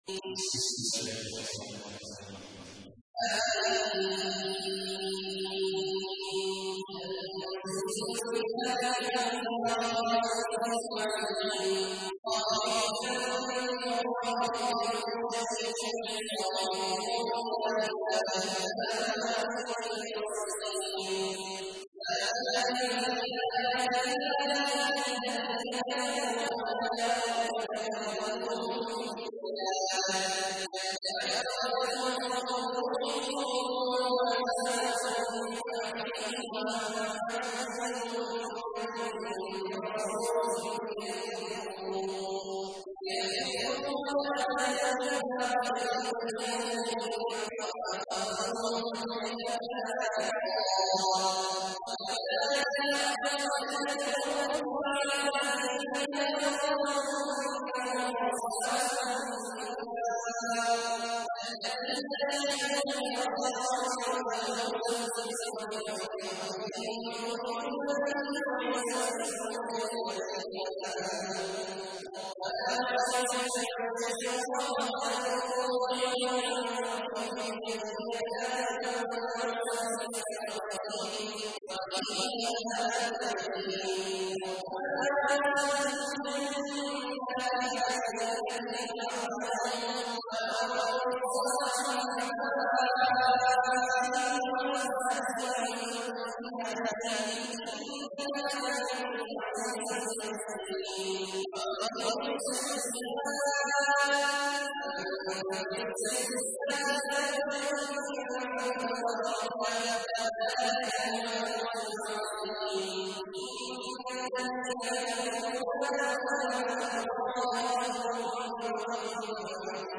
تحميل : 40. سورة غافر / القارئ عبد الله عواد الجهني / القرآن الكريم / موقع يا حسين